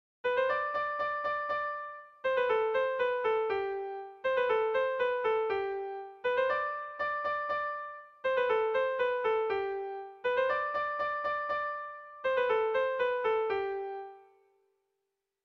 Haurrentzakoa
Zazpi puntukoa, berdinaren moldekoa
AB